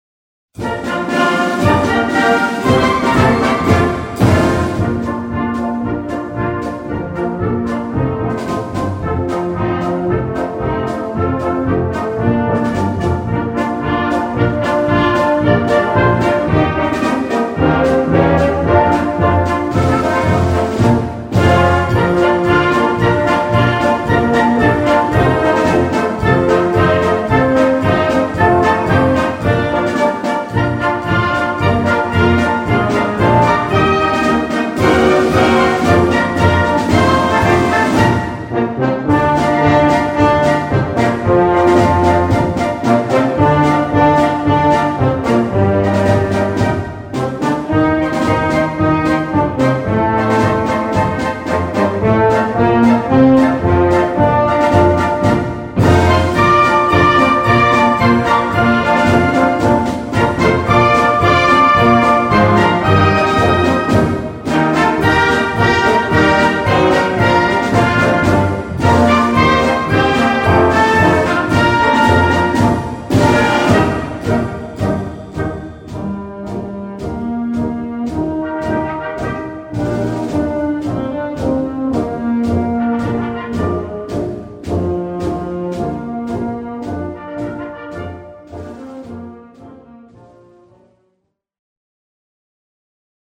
Répertoire pour Harmonie/fanfare - Défilé et parade